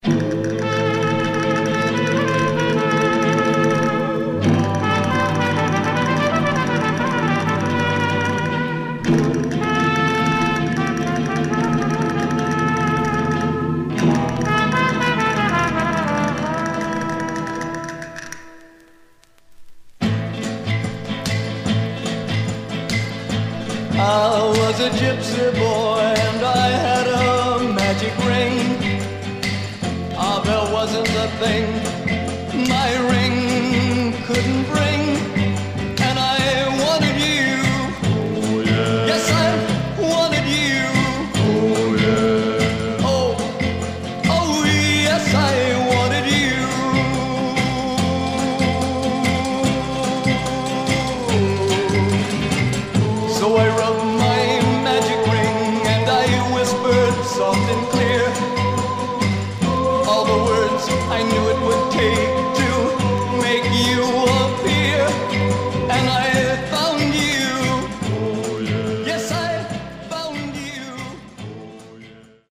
Mono
Teen